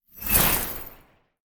Free Frost Mage - SFX
ice_teleport_in_02.wav